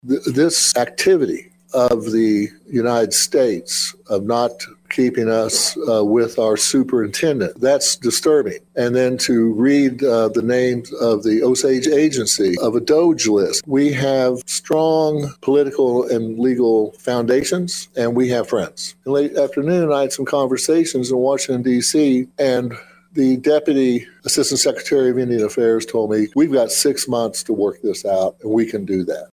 Chief Standing Bear Gives a State of the Nation Speech
On Saturday afternoon, Osage Nation Principal Chief Geoffrey Standing Bear gave a State of the Nation speech. During that speech, Standing Bear highlighted the recent issue that has arisen between the federal government and the Bureau of Indian Affairs.